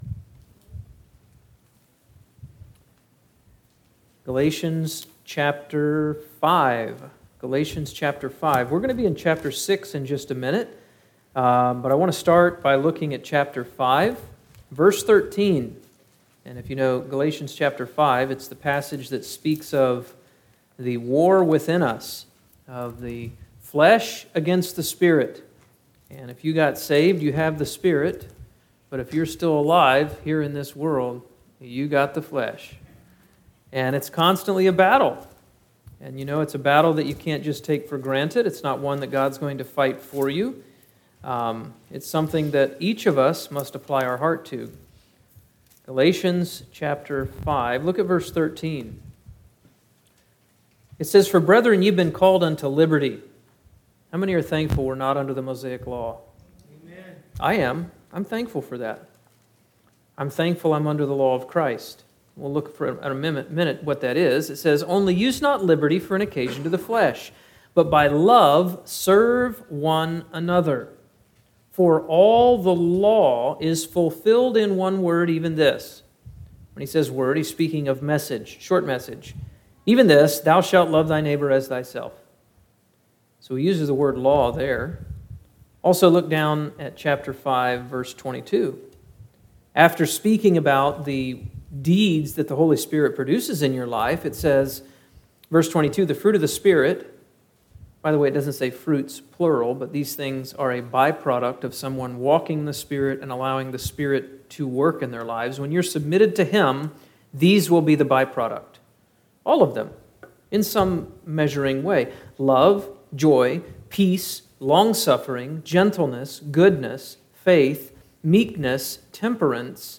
A message from the series "Topical Messages."